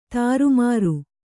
♪ tāru māṛu